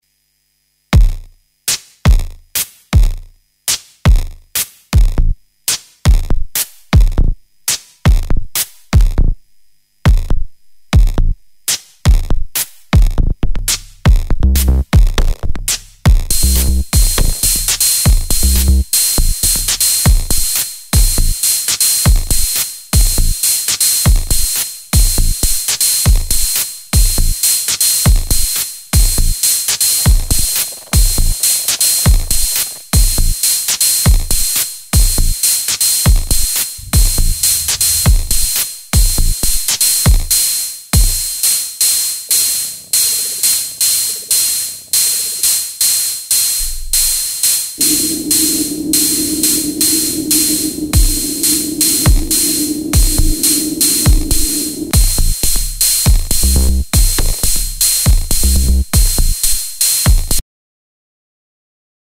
These are forensic, high-intensity impacts designed to inhabit the absolute extremes of the frequency spectrum.
• The Zero-FX Rule: None of these patches use Reverb or Delay. They are bone-dry, surgical, and raw. They rely on the Digitone’s FM engine to provide the air and the grit.
Suitable for Minimal Techno, Noise, IDM, Glitch, Drone and more.